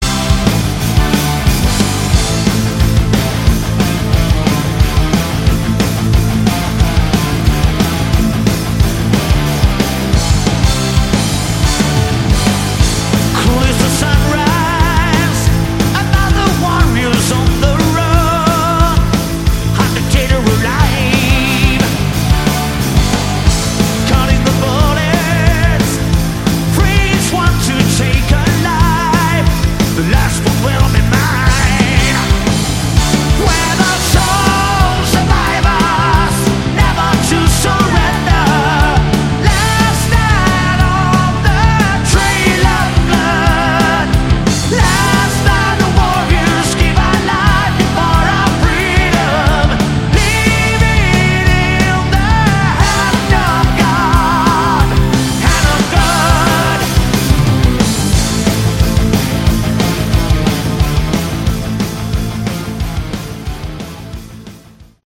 Category: Melodic Metal
vocals
guitar
bass
drums
keyboards